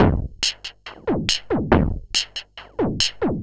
tx_perc_140_chunkgrit.wav